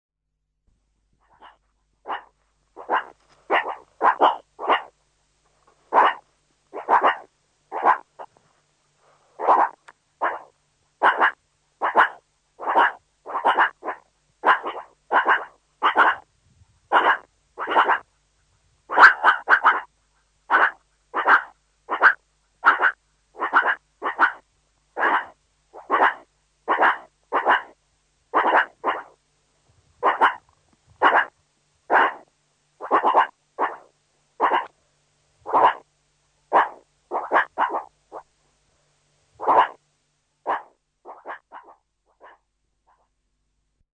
Звук тревоги из норы при виде шакала от юного суриката